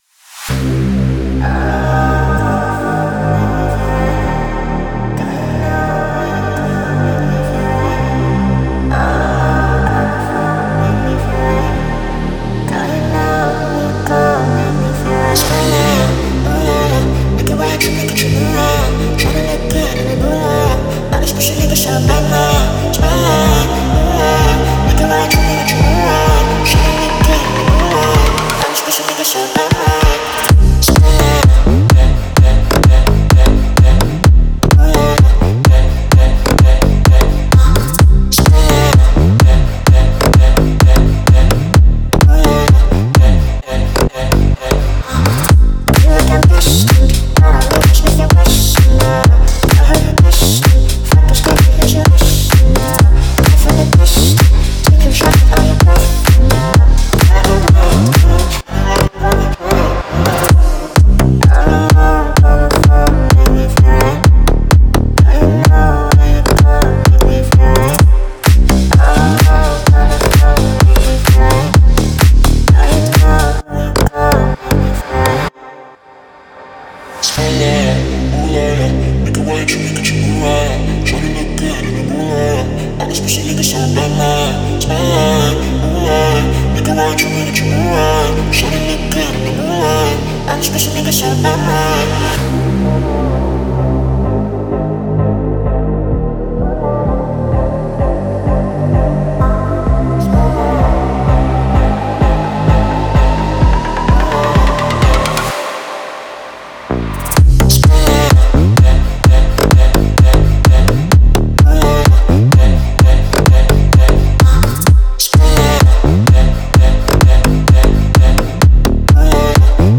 это атмосферная трек в жанре электронной музыки